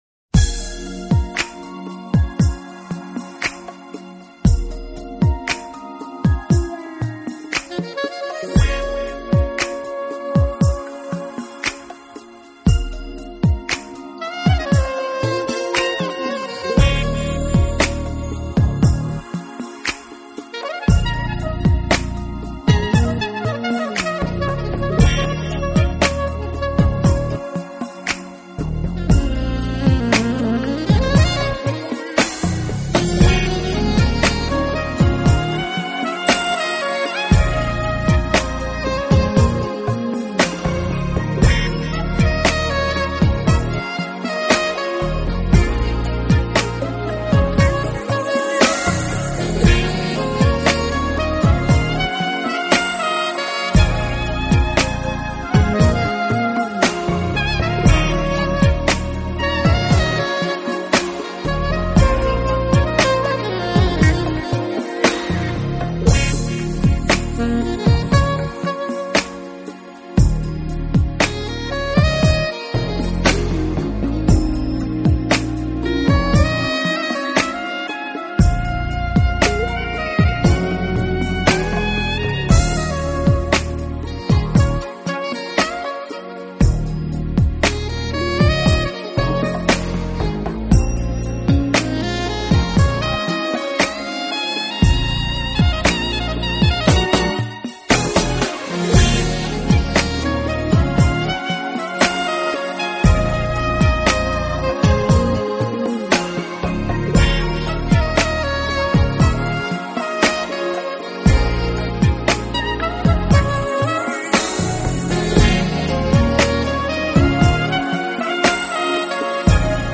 【爵士萨克斯】
偏于布鲁斯和流行爵士。